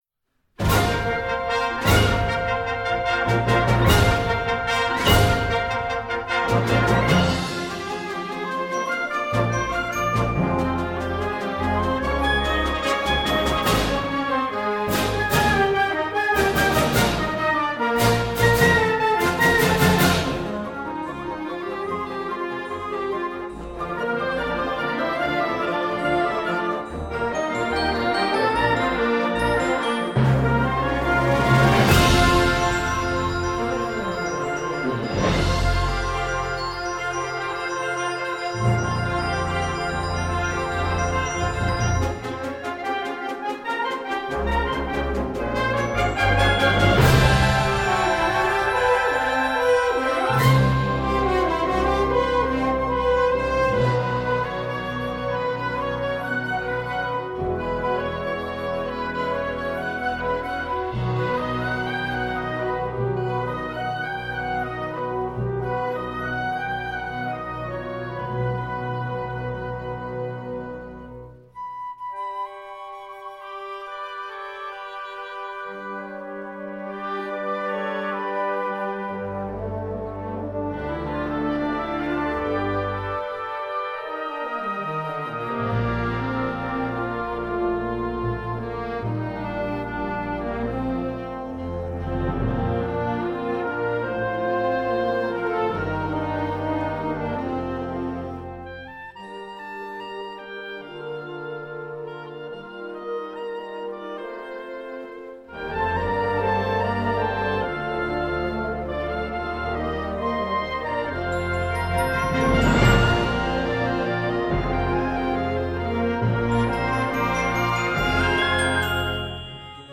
Gattung: Konzertwerk
5:00 Minuten Besetzung: Blasorchester PDF